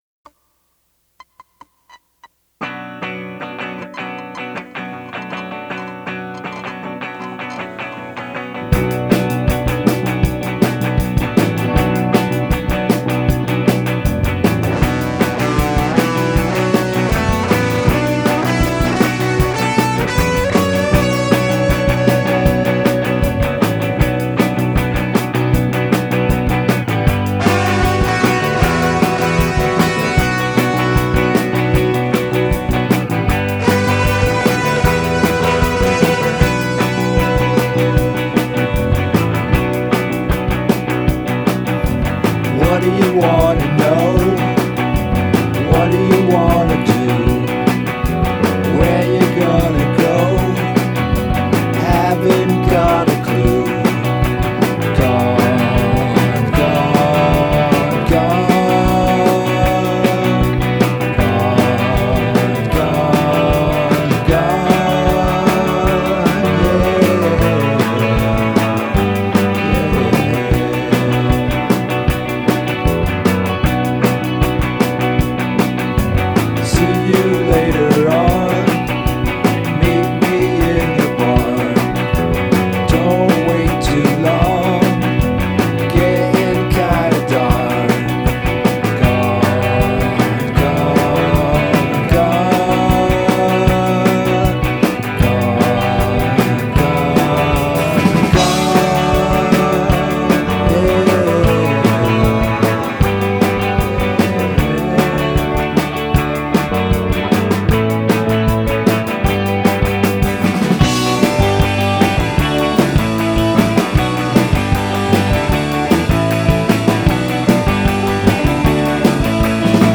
stronger, heavier guitar sound